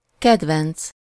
Ääntäminen
Synonyymit favorisant propice bénéfique Ääntäminen France: IPA: [fa.vɔ.ʁabl] Haettu sana löytyi näillä lähdekielillä: ranska Käännös Ääninäyte Adjektiivit 1. kedvenc Suku: f .